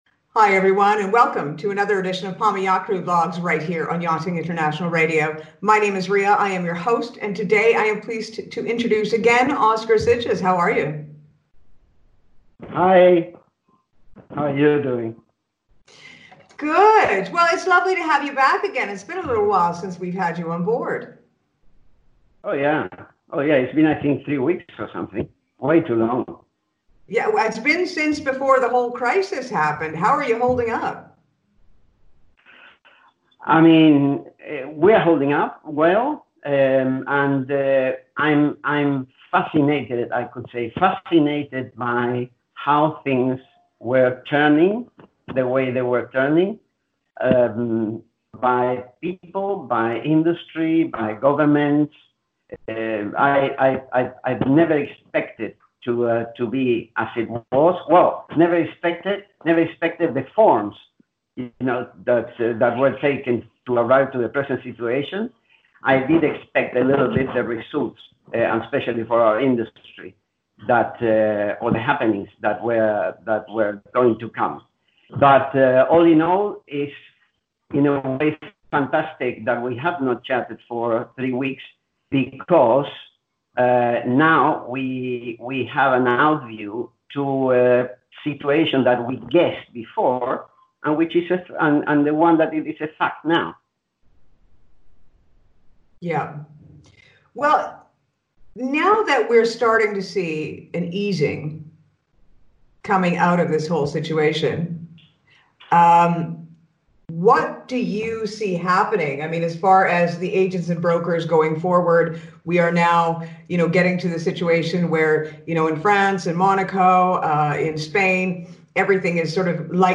A highly informative interview with insight that only an industry insider of many year...